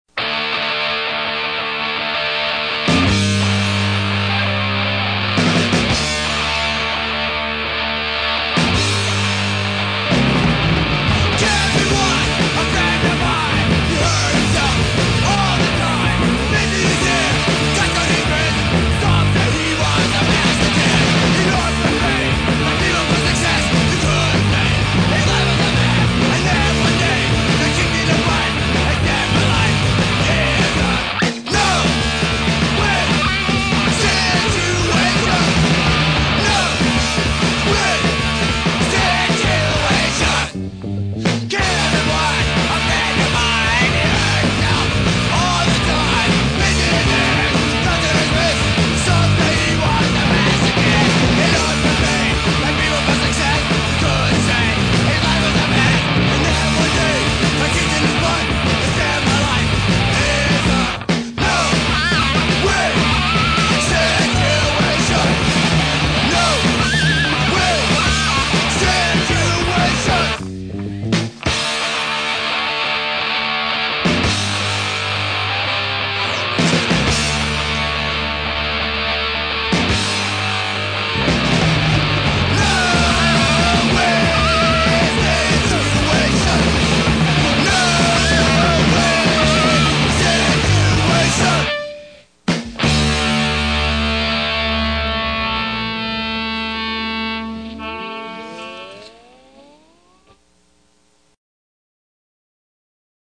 hardcore punk rock
punk rock See all items with this value